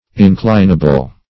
Inclinable \In*clin"a*ble\, a. [L. inclinabilis.